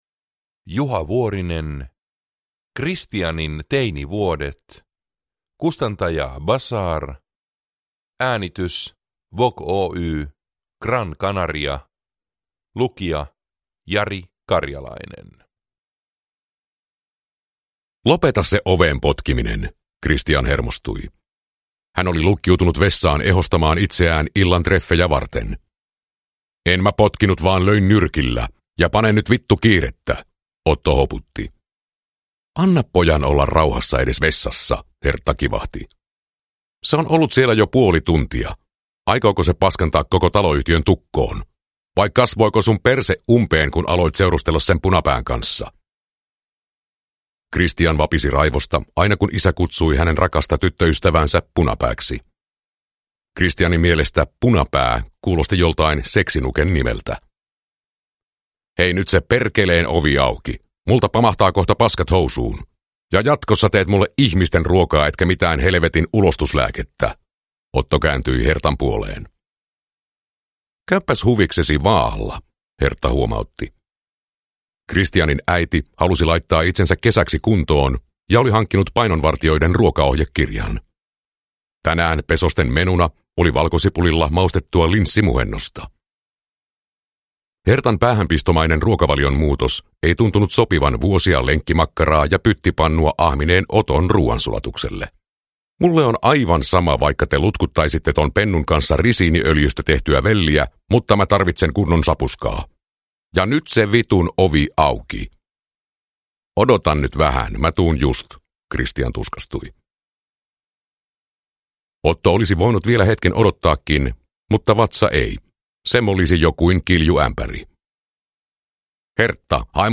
Kristianin teinivuodet – Ljudbok